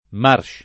marš] (meno com. marc’ [mar©]) escl. — non marschmarshmarch — antiq. in It. l’uso dell’originaria gf. fr. marche [fr. marš] («marcia» s. f.): come un tamburo, che suona a raccolta e dice: marche [